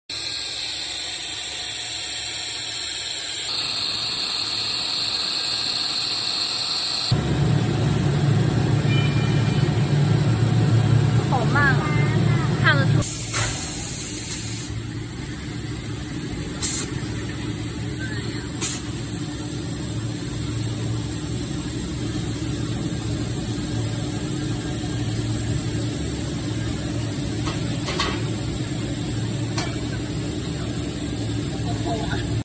Three Layer PPR Pipe extruder sound effects free download
Three Layer PPR Pipe extruder machine Production Line